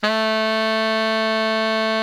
BARI  FF A 2.wav